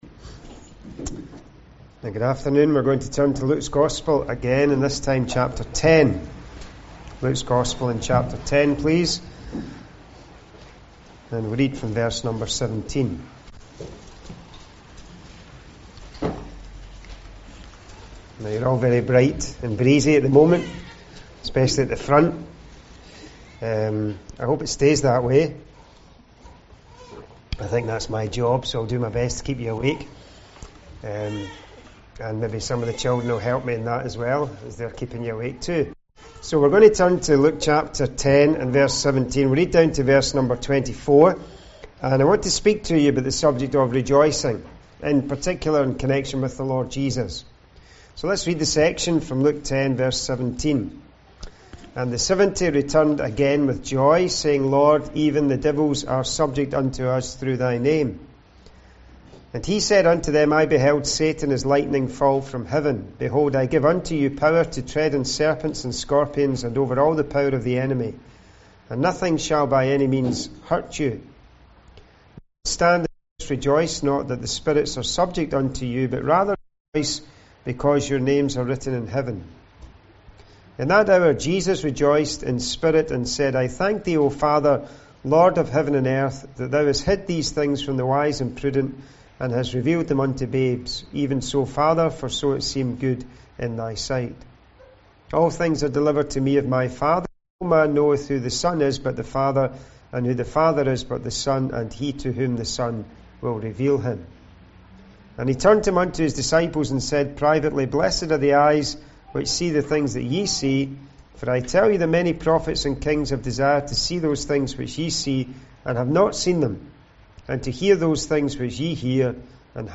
A group of independent Christians who gather to the Name of the Lord Jesus Christ in Northampton.
Service Type: Ministry